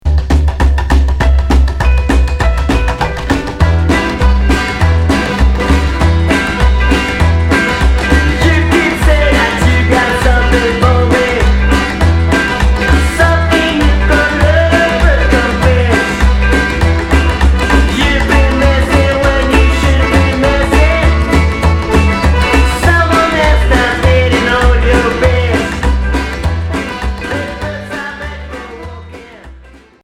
Folk Rock